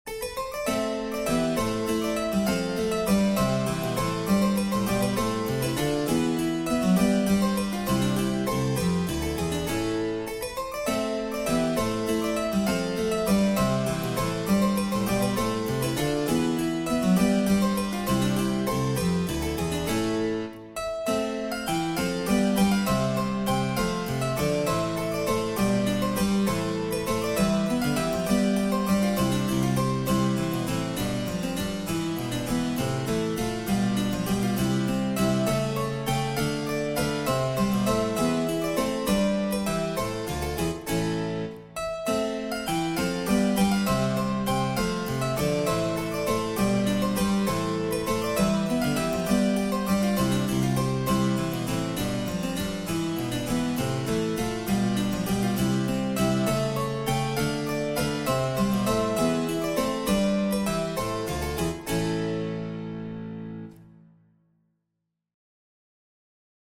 Small early baroque Corrente for keyboard